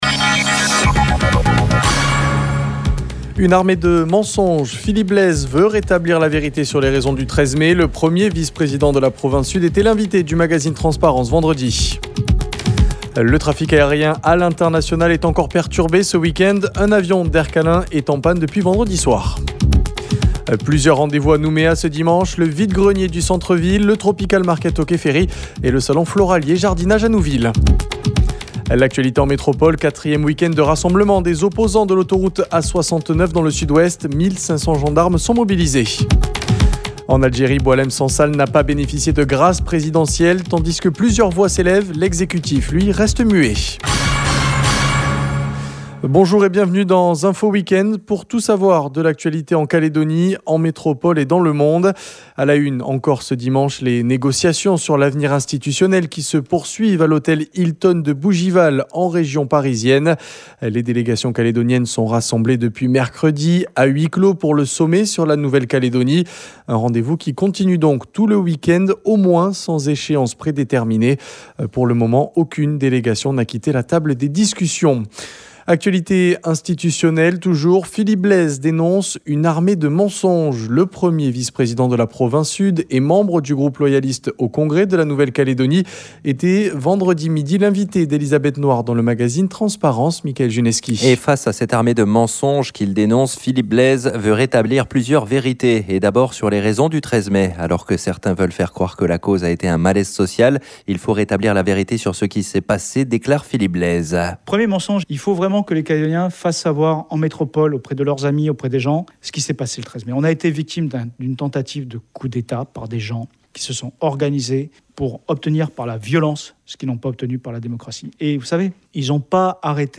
JOURNAL : INFO WEEK-END DIMANCHE MIDI 06/07/25